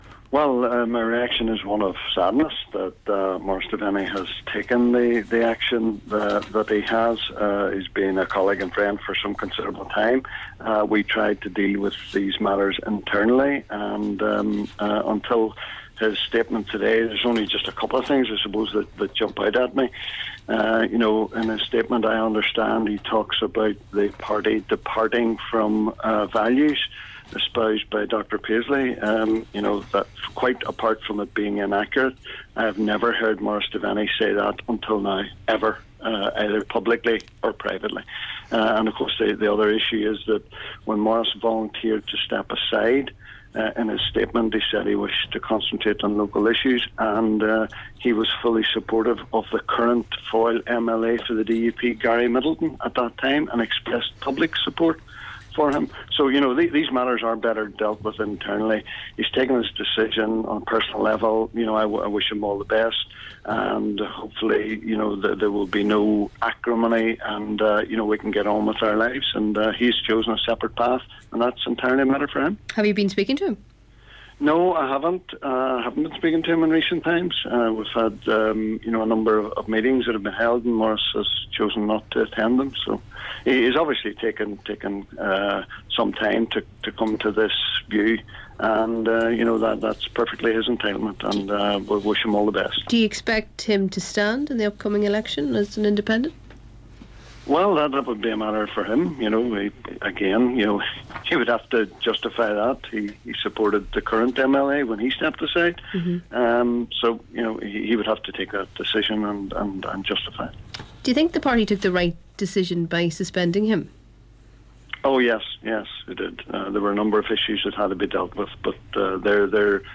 LISTEN: DUP MP Gregory Campbell reacts to Maurice Devenney's resignation from party